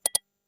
mouse3.wav